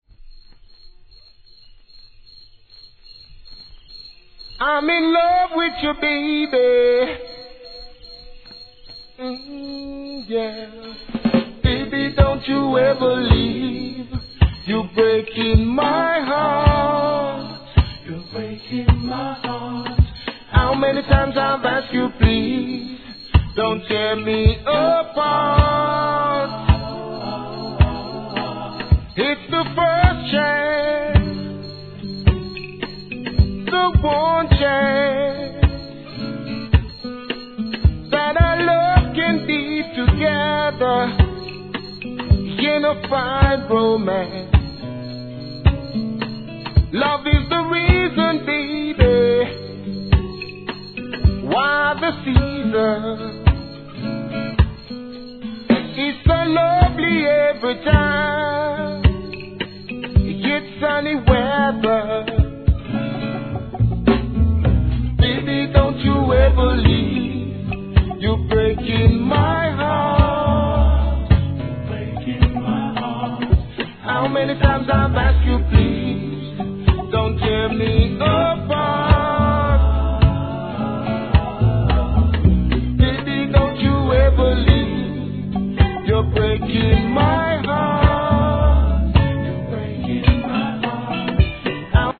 JAPANESE REGGAE
アコギのメロディにスティールパンの音色が何とも優しげなサウンド♪